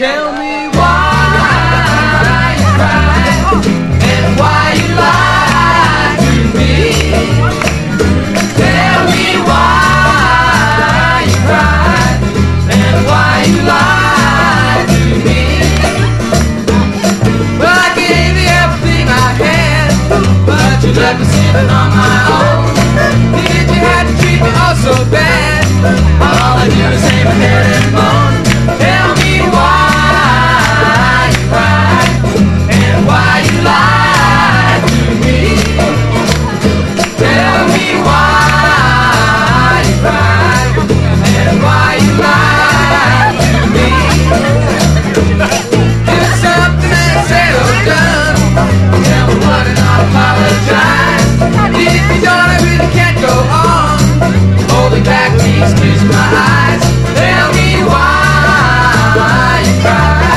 ROCK / 60'S / ROCKABILLY